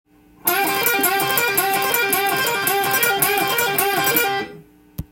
【王道ブルースロックフレーズ】
譜面通りのピッキングで弾いてみました
弾いていたロック＆ブルース系　御用達フレーズです。